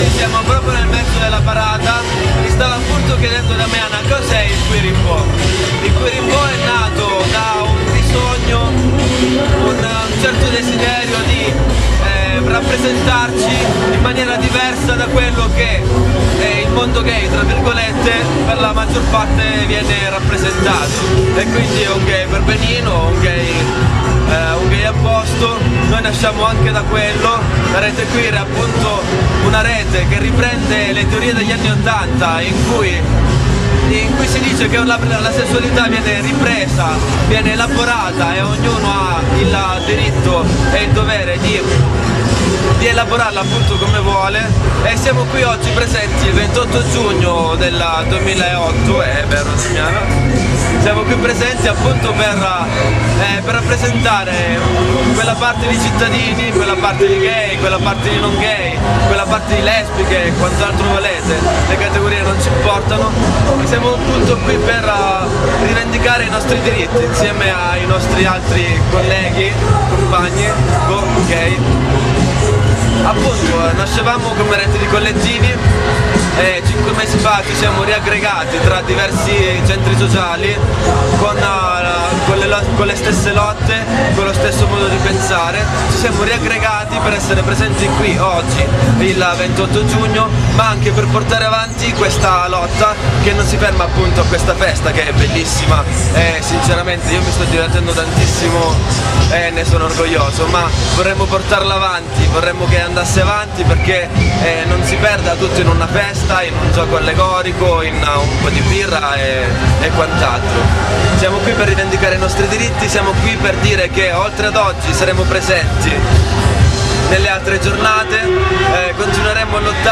GLBTQ Pride 2008
Raccogliamo qualche valutazione della giornata.